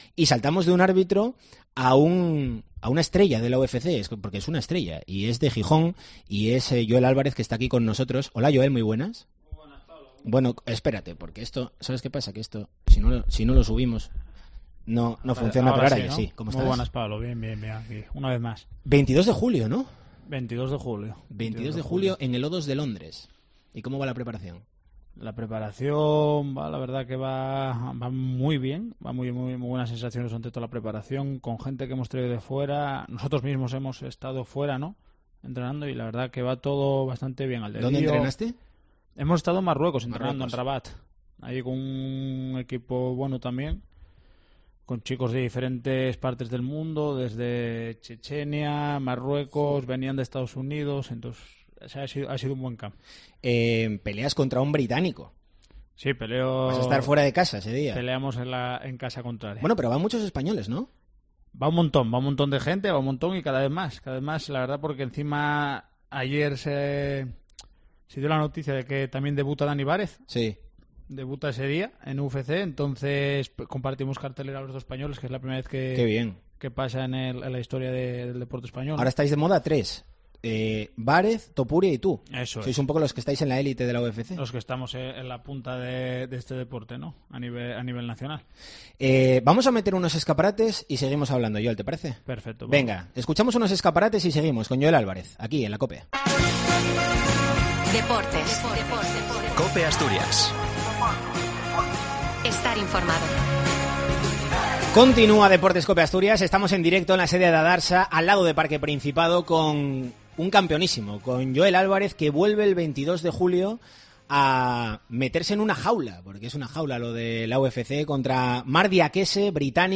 ENTREVISTA COPE ASTURIAS